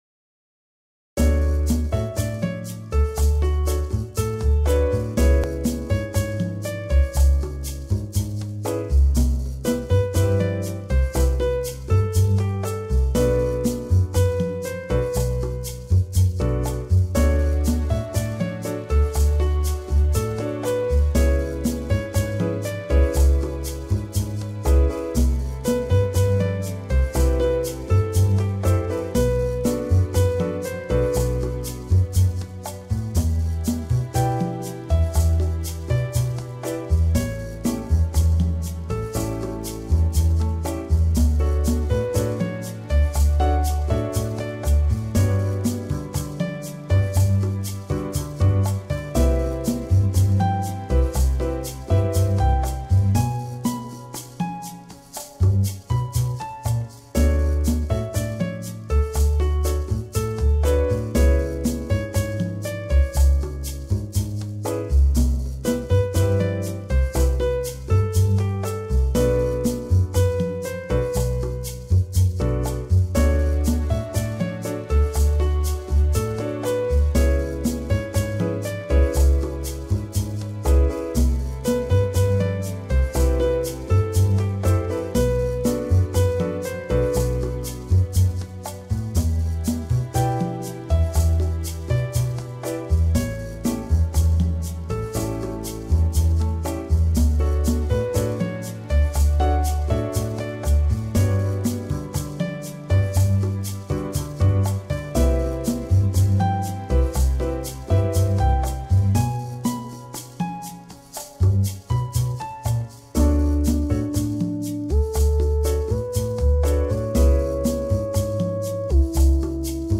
Músicas Relaxante e Tranquilizante